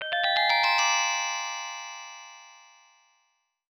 Ascending Mystery 1.wav